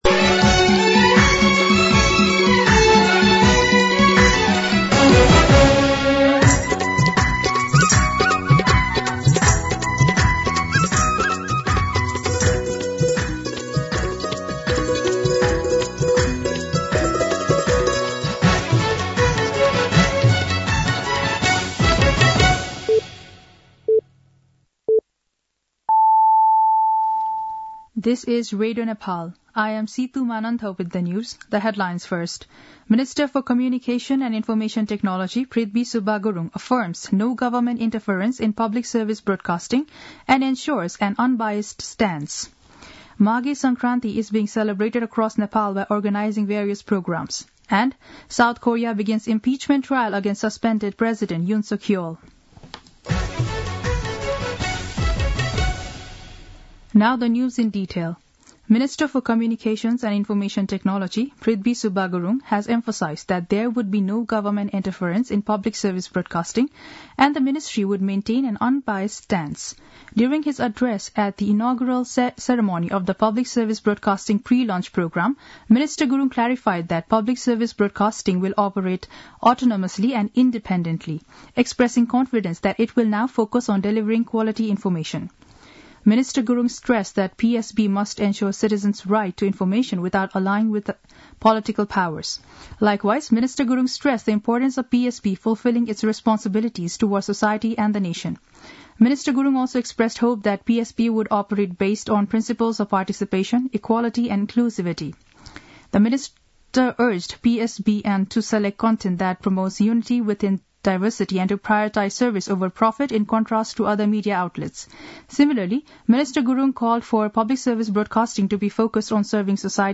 दिउँसो २ बजेको अङ्ग्रेजी समाचार : २ माघ , २०८१
2pm-English-News.mp3